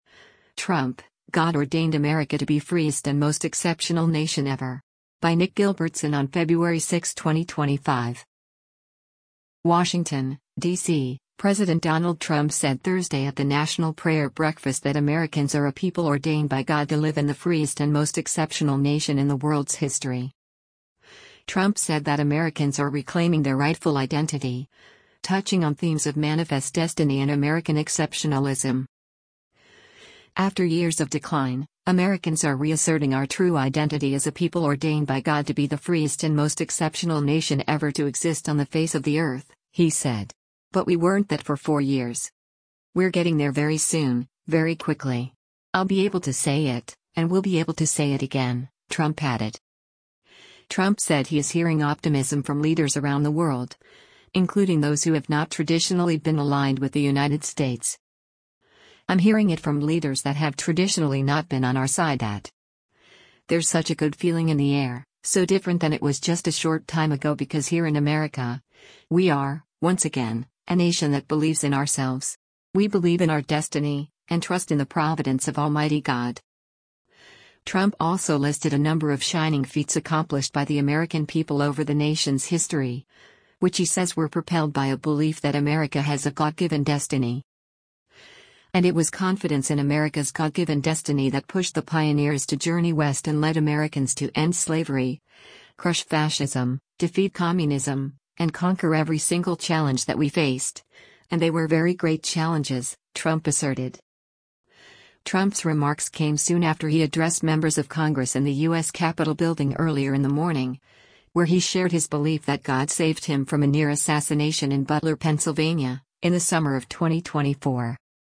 U.S. President Donald Trump speaks at the National Prayer Breakfast sponsored by the The F